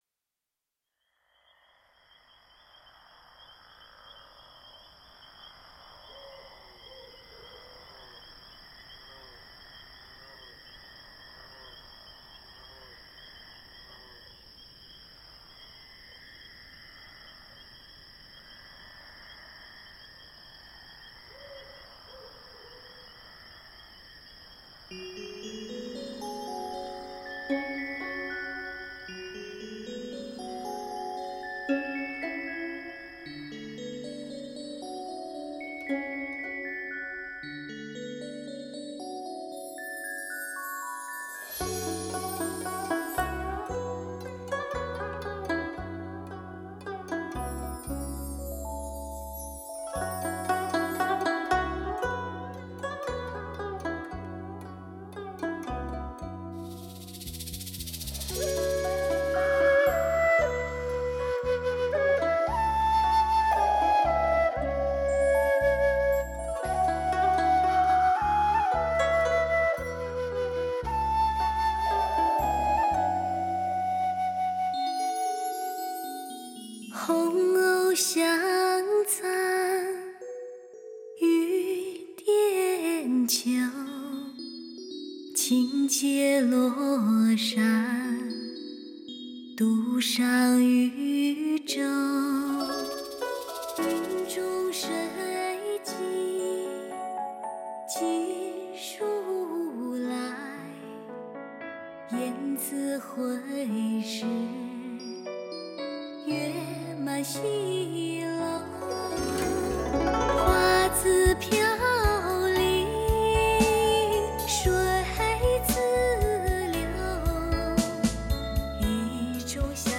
空灵纯净的嗓音，遥远而神秘的韵味让你身醉、心醉、神醉、魂醉。